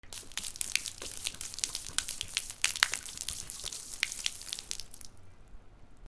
水をこぼす
『バシャバシャ』